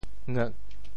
喫 部首拼音 部首 口 总笔划 12 部外笔划 9 普通话 chī 潮州发音 潮州 ngeg4 文 中文解释 吃 <动> (形声。
ngeuk4.mp3